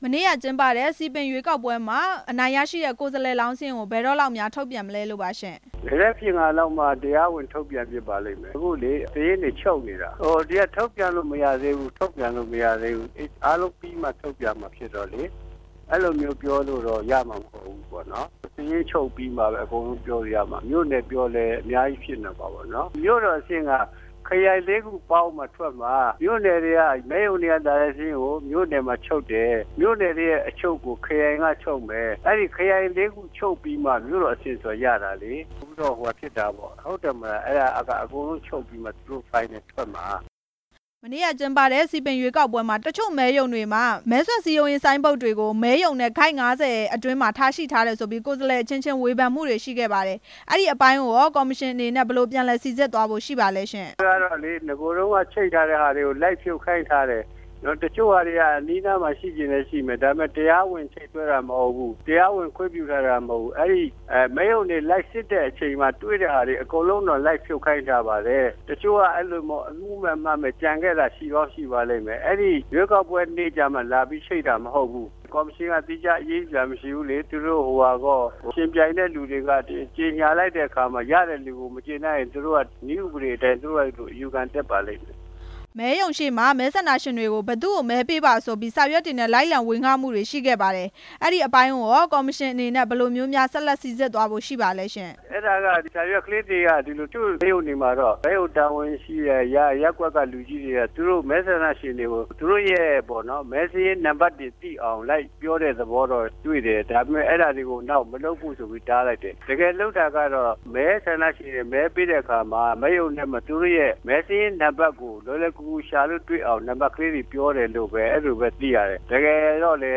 စည်ပင်ရွေးကောက်ပွဲကို ဝေဖန်မှုတွေကိစ္စ ကော်မရှင် ဥက္ကဌ ဦးတင်အေးနဲ့ မေးမြန်းချက်